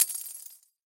Звуки тамбурина
Звук удара тамбурина о пол